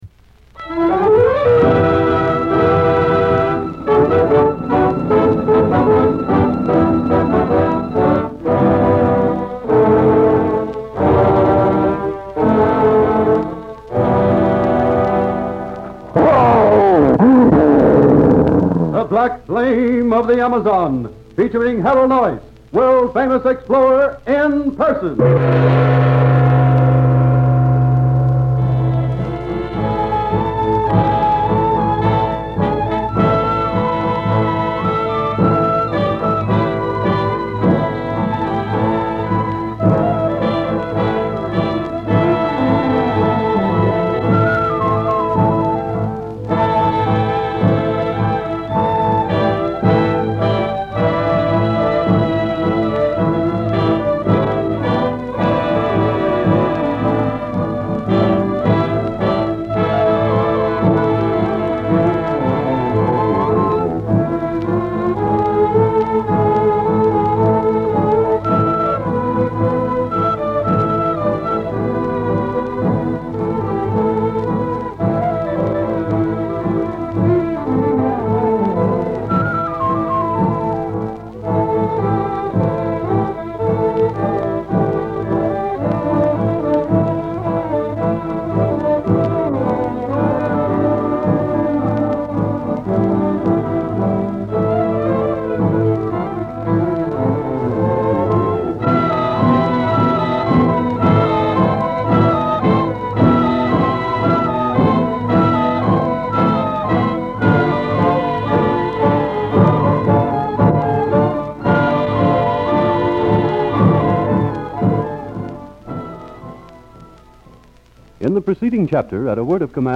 One such embodiment of this timeless intrigue is the old-time radio show 'The Black Flame of the Amazon.' Premiering on February 14, 1938, this series took its audience on a thrilling journey through the dense jungles of South America, following the exploits of the intrepid explorer Harold Noice.